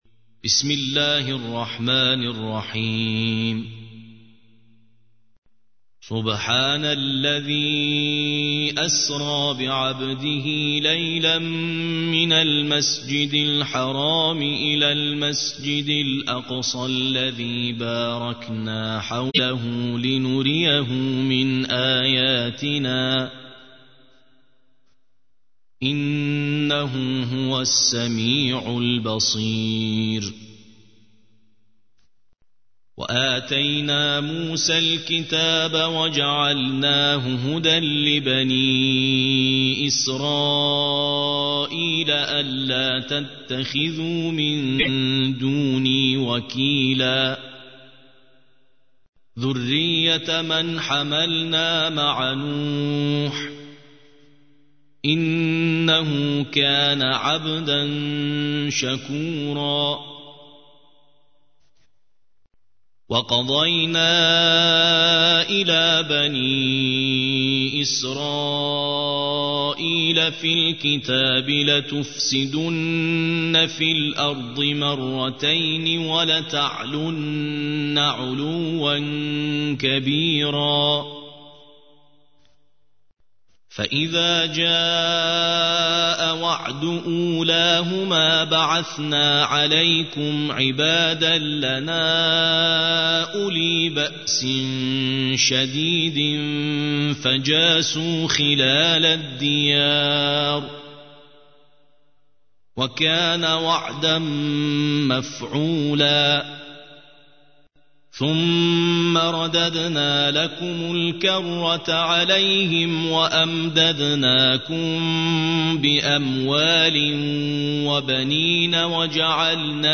ترتیل سوره اسراء